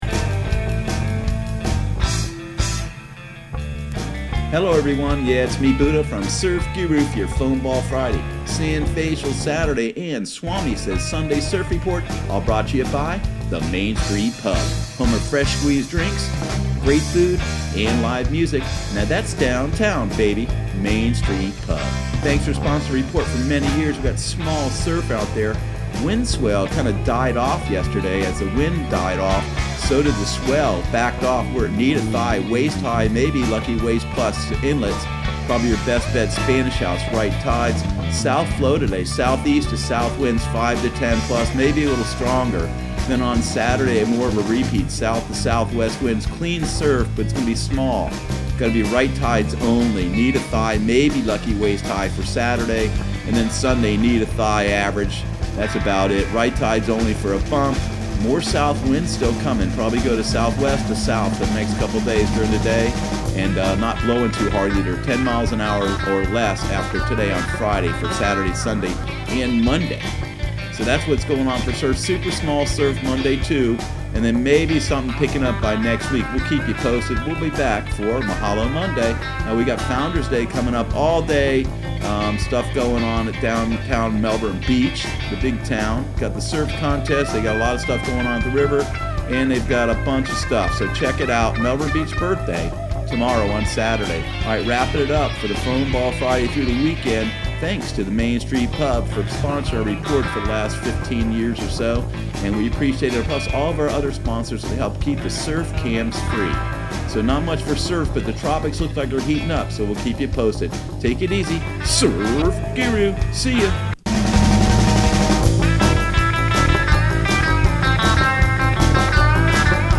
Surf Guru Surf Report and Forecast 05/03/2019 Audio surf report and surf forecast on May 03 for Central Florida and the Southeast.